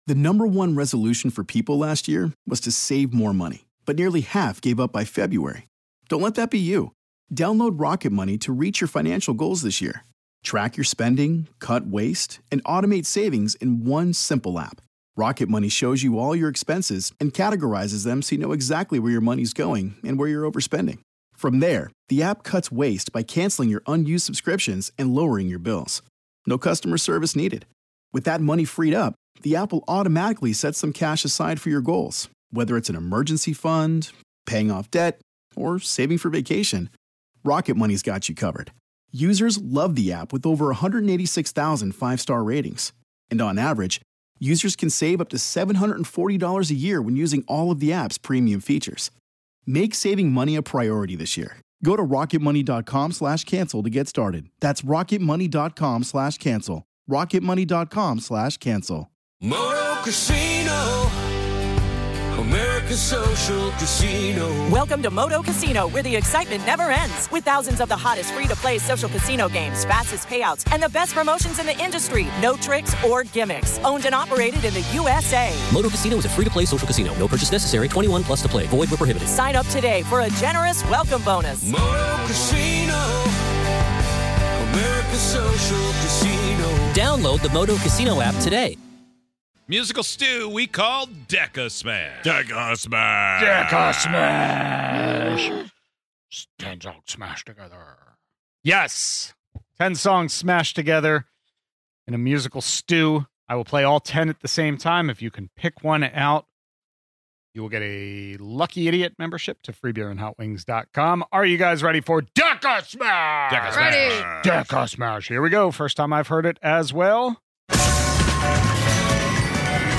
all from musicians who have moved to the afterlife, smashed them all together, and played them at the same time. Can you guess any of the 10 songs from DECASMASH?